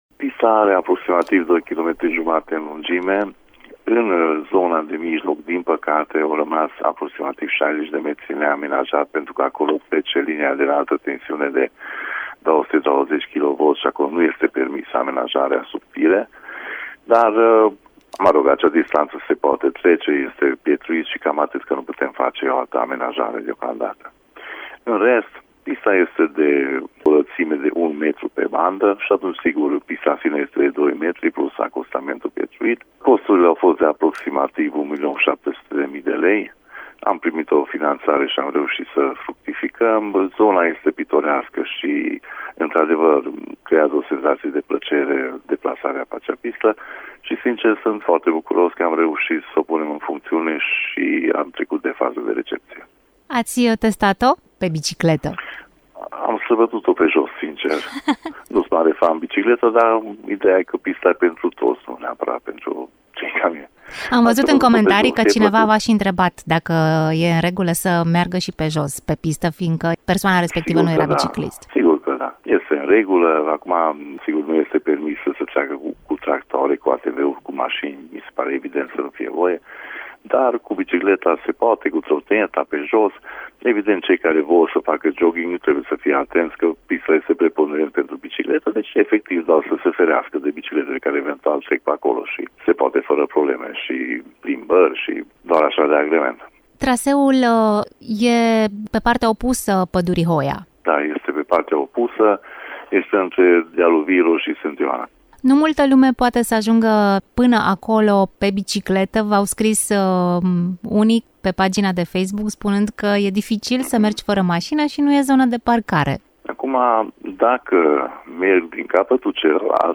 Este, totuși, cu suișuri și coborâșuri, așa că am vorbit cu primarul comunei Baciu, Balázs János, și despre plusuri, și despre minusuri.